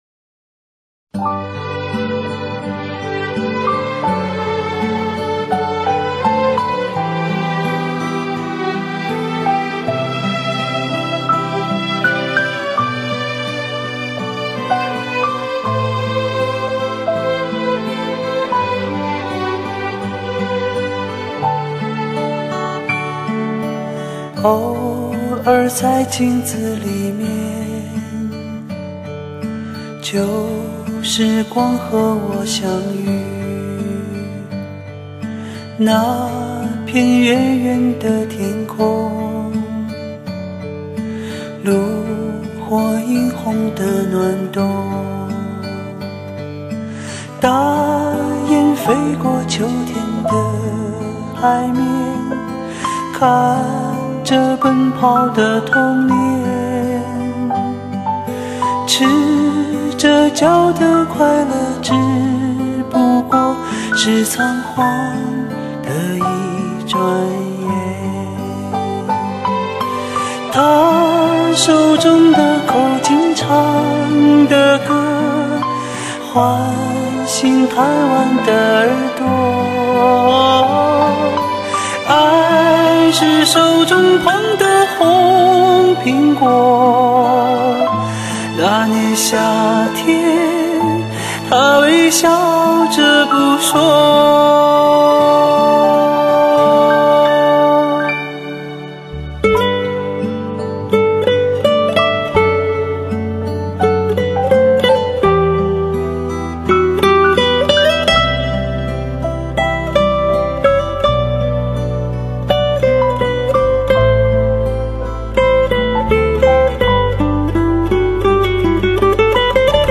整张专辑给人的感觉是两个字：清澈。
这张专辑的编曲上运用了大量的弦乐